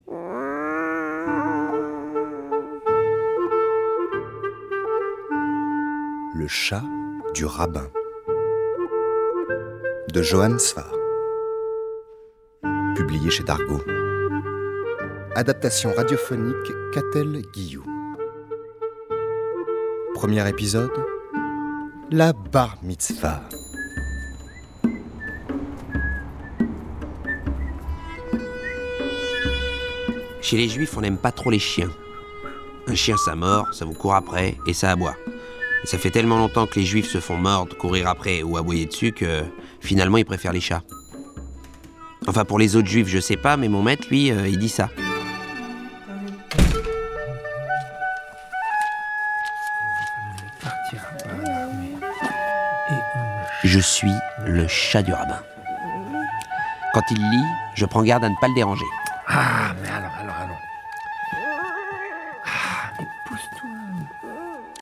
Feuilleton en 10 épisodes
Un petit extrait du feuilleton radiophonique :